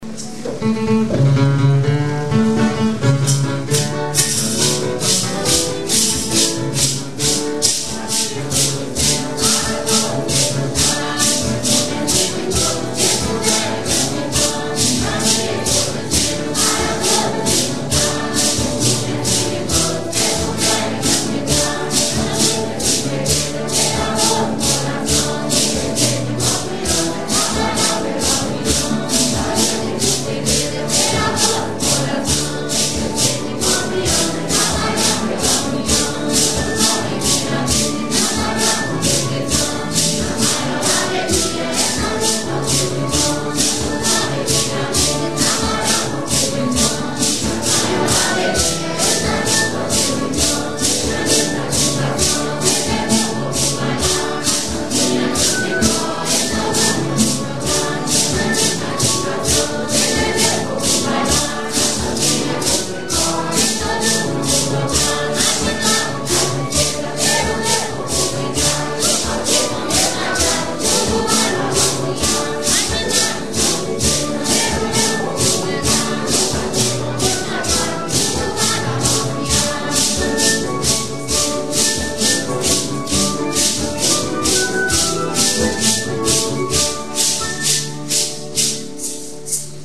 marcha